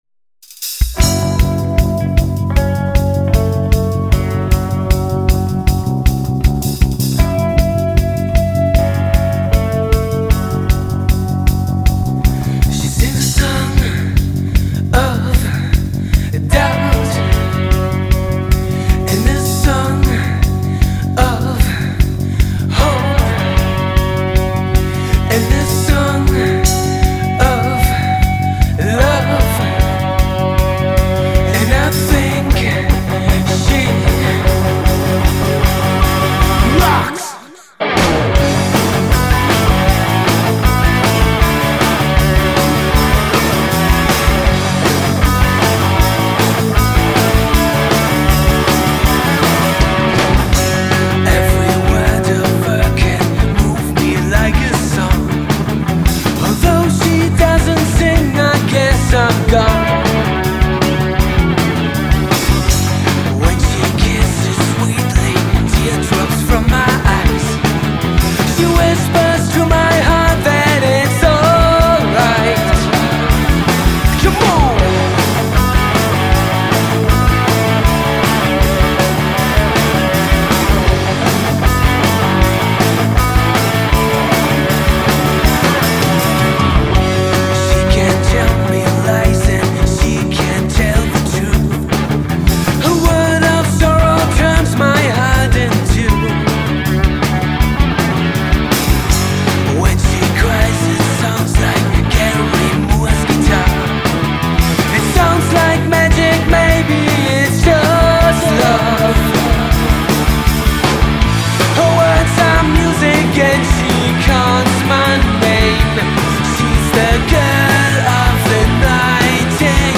Aufgenommen bei SAE in Hamburg.